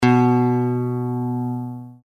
Use the audio tones below to tune your guitar to an Open E Tuning (commonly used for playing slide).
B String